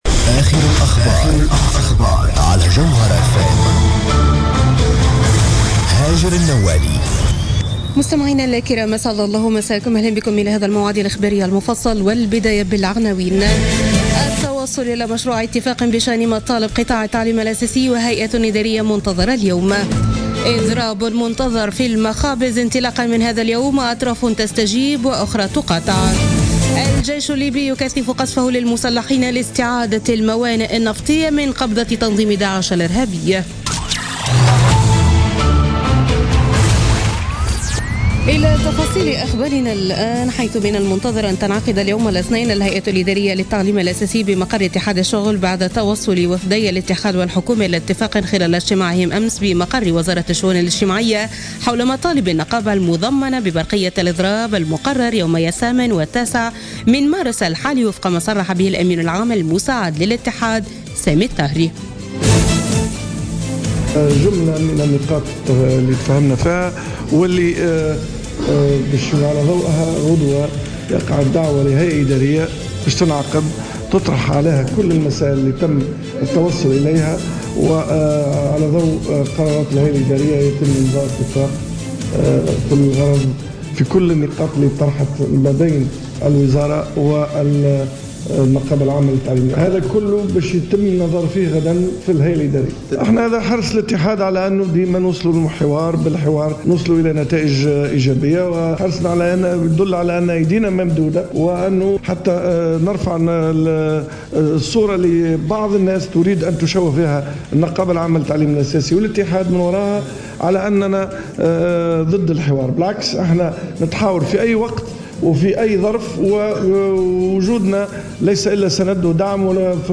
نشرة أخبار منتصف الليل ليوم الإثنين 6 مارس 2017